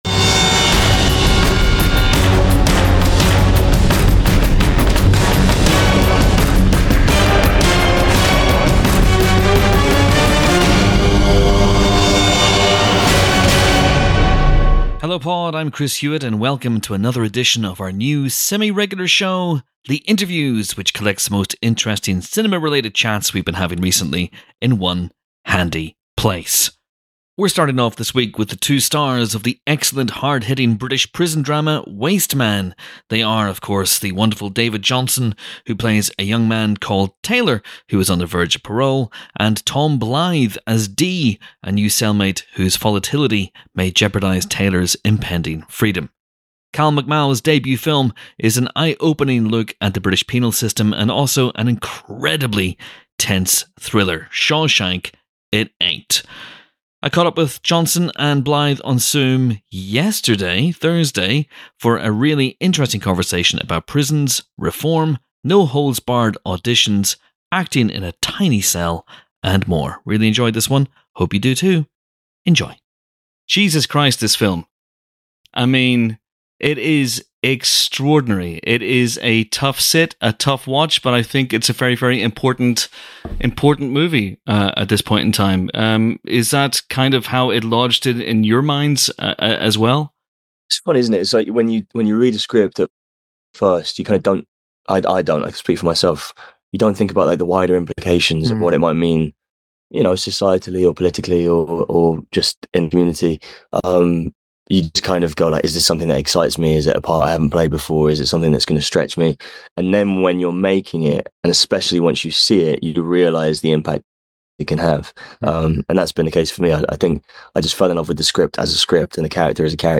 Three cracking interviews about three wildly different subjects.